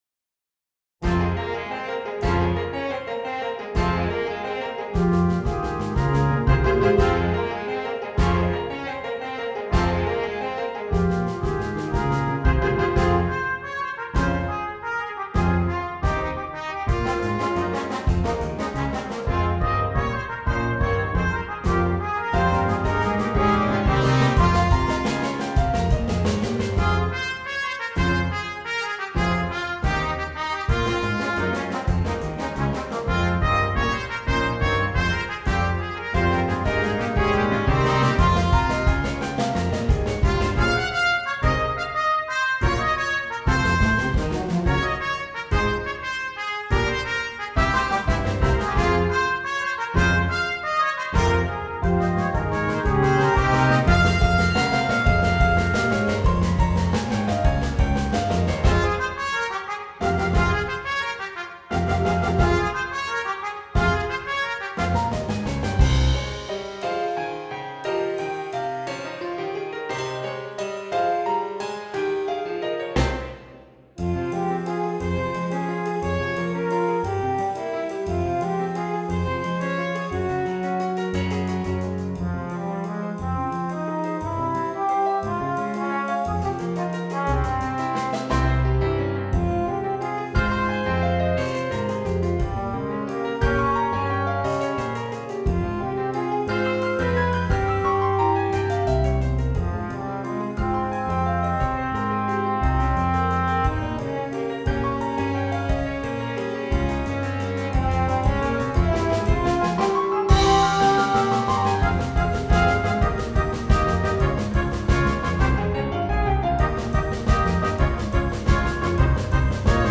Brass Quintet and Rhythm Section